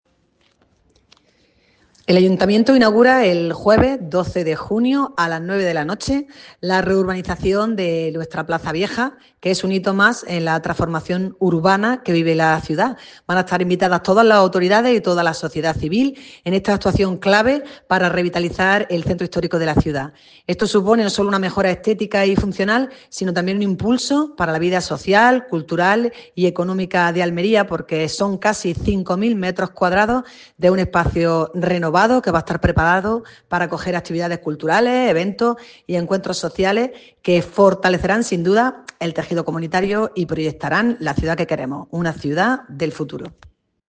ALCALDESA-MARIA-DEL-MAR-VAZQUEZ-FECHA-INAUGURACION-PLAZA-VIEJA.mp3